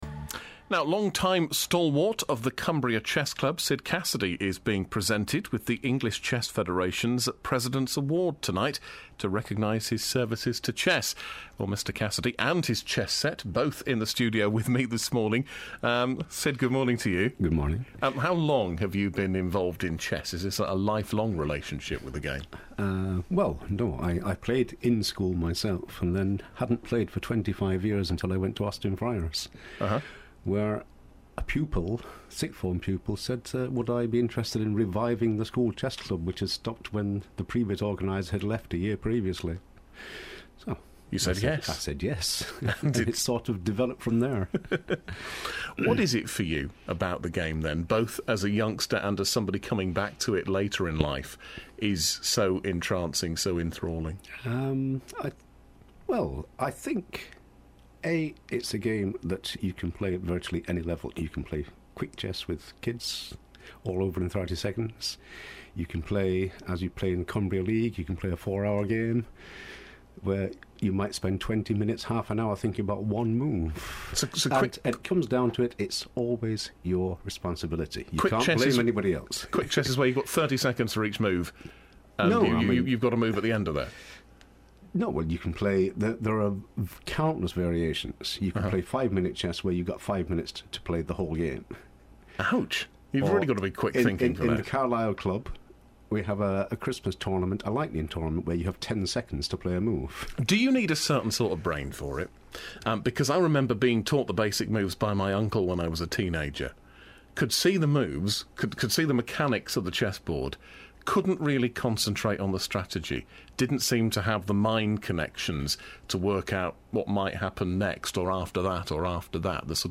Radio Cumbria Interview.
joinedinterview.mp3